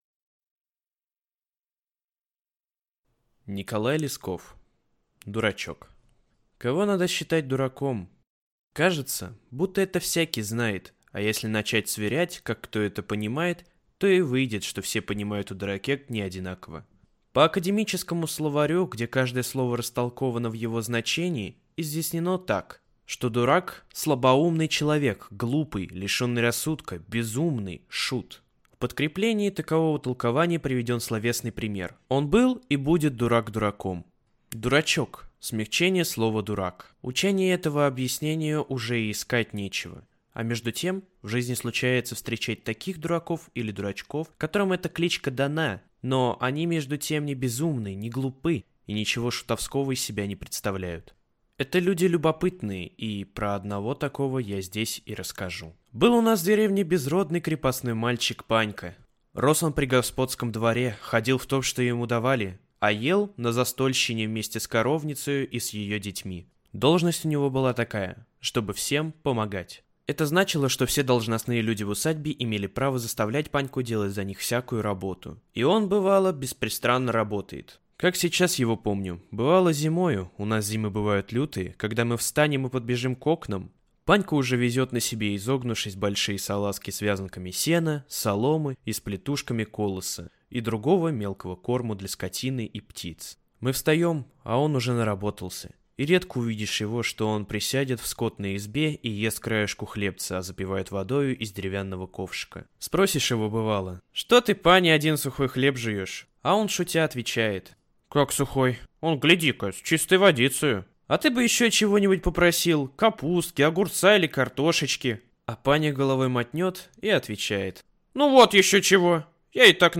Аудиокнига Дурачок | Библиотека аудиокниг